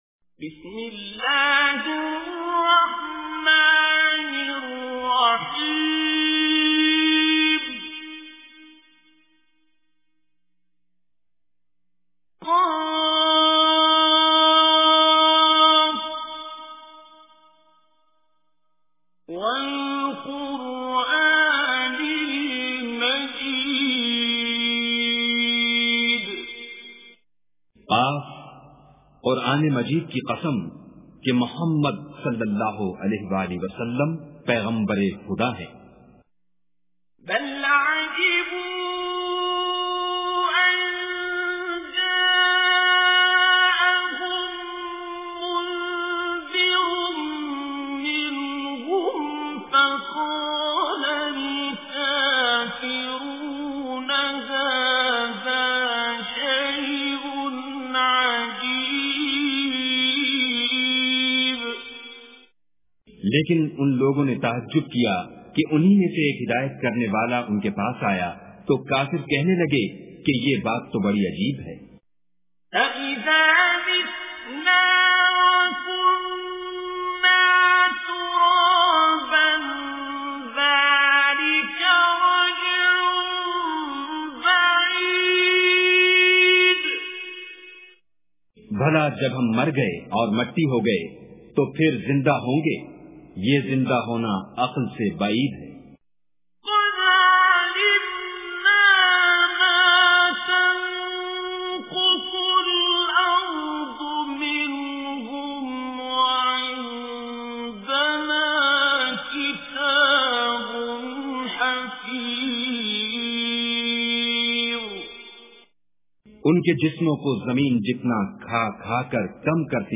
Surah Qaf Recitation with Urdu Translation
Surah Qaf is 50th Surah of Holy Quran. Listen online and download mp3 tilawat or recitation of Surah Qaf in the beautiful voice of Qari Abdul Basit As Samad.